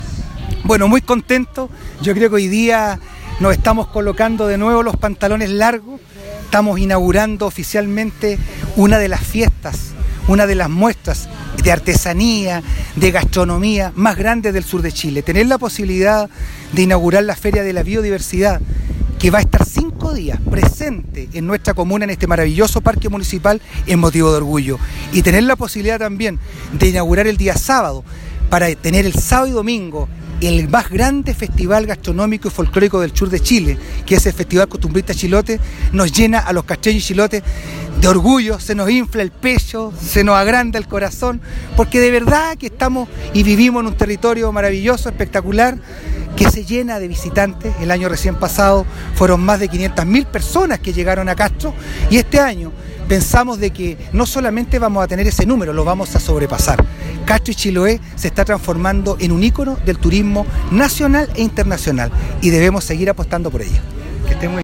cuña-alcalde-vera-tema-feria-biodiversidad-2018.mp3